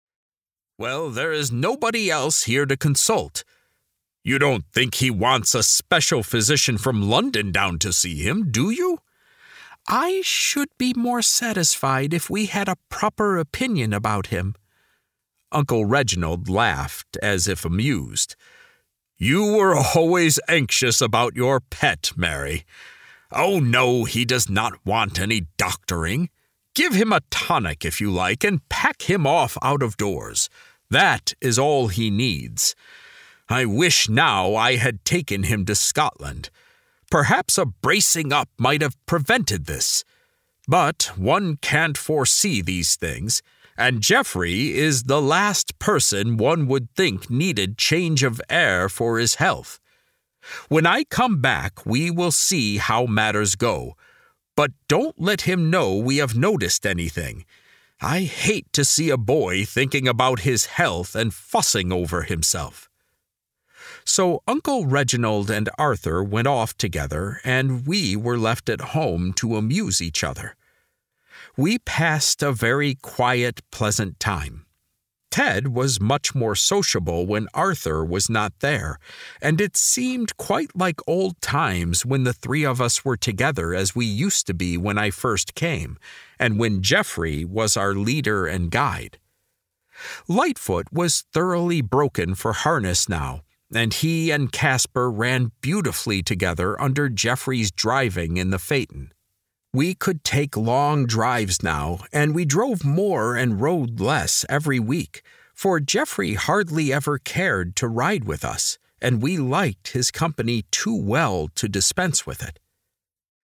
This is an audiobook, not a Lamplighter Theatre drama.
True-to-the-Last-Audiobook-Sample.mp3